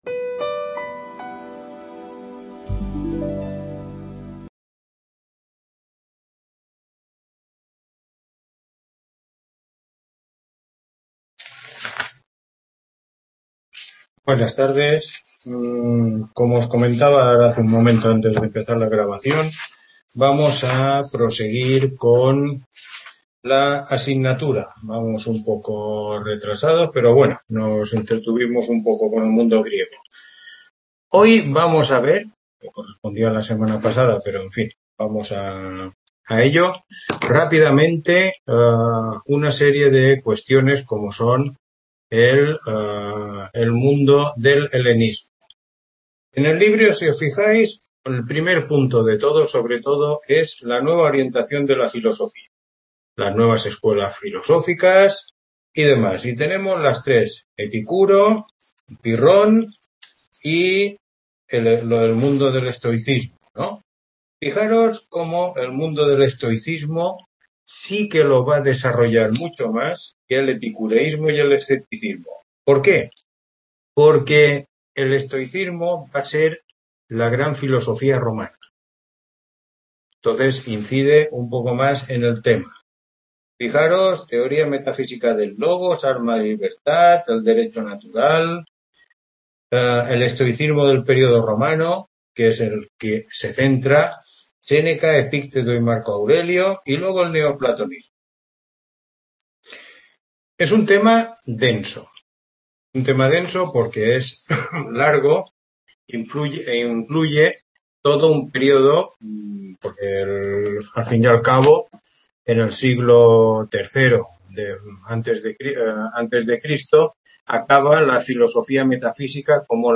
Tutoría 7